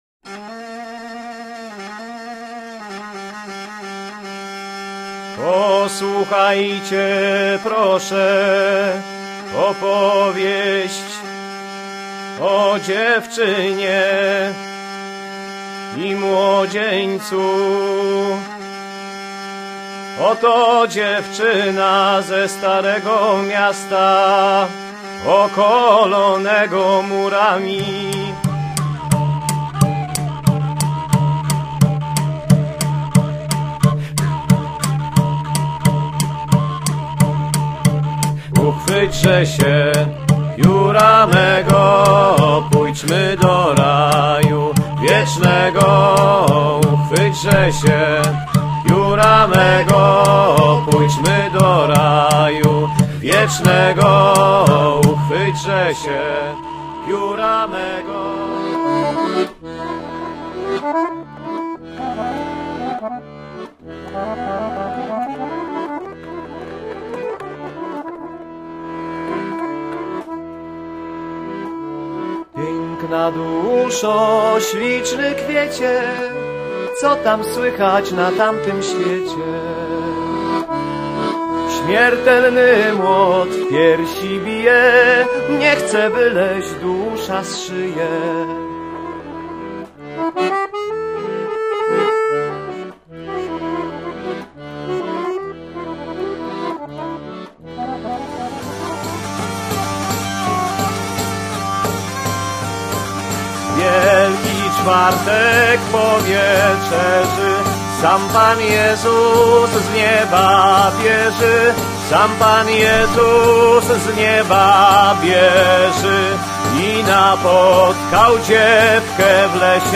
Lira korbowa / Pieśni Dziadów, słowiańskich aojdów